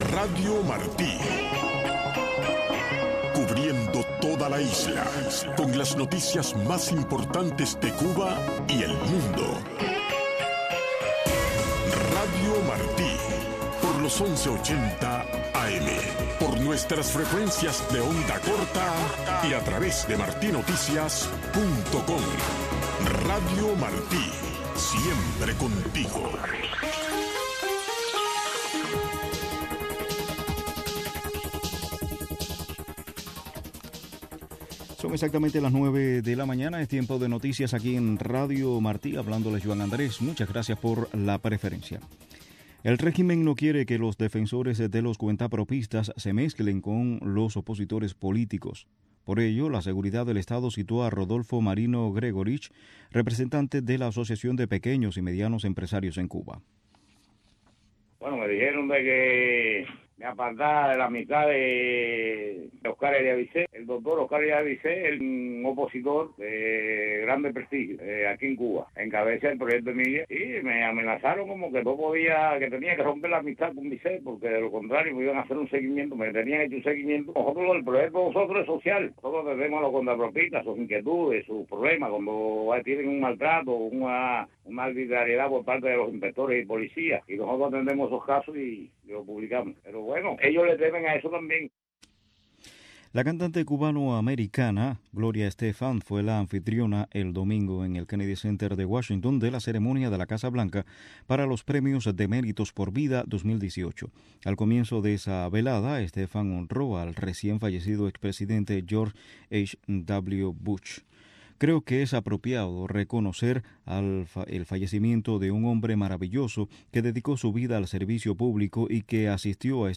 Testimonios de los protagonistas.